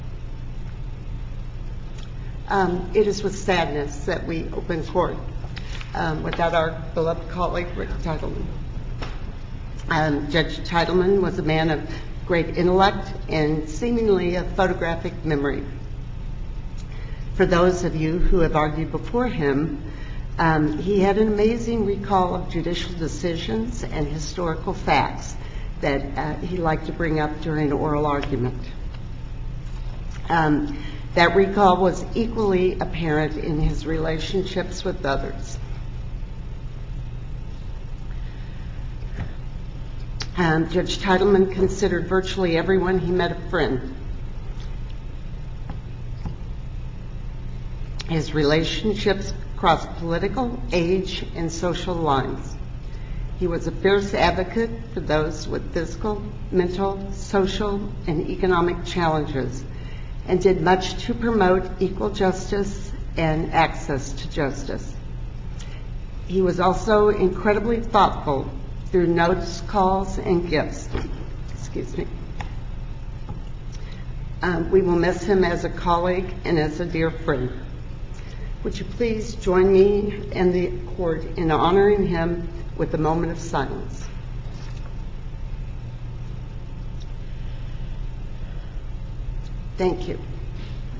Before arguments resumed, Chief Justice Patricia Breckenridge offered, on behalf of the Court,
brief remarks in tribute to Judge Teitelman before asking for a moment of silence in his memory.
Chief Justice Breckenridge offers a tribute to Judge Teitelman